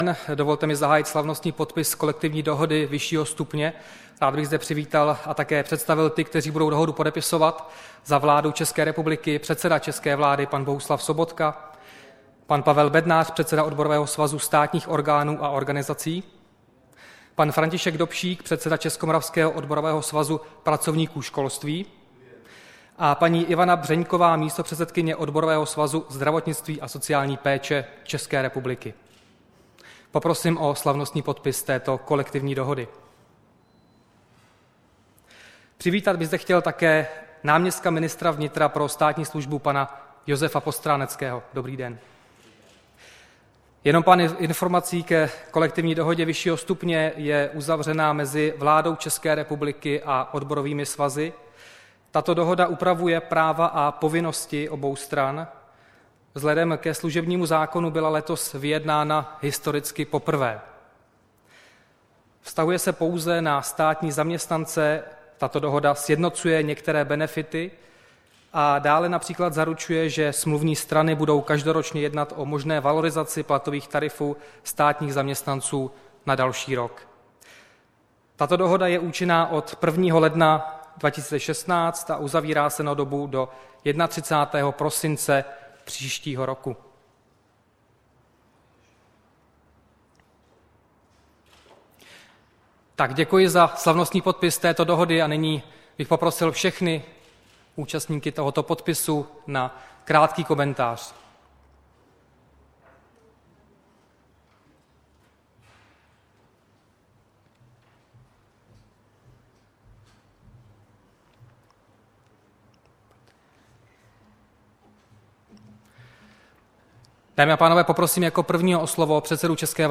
Tisková konference po podpisu kolektivní smlouvy se zástupci zaměstnanců, 22. prosince 2015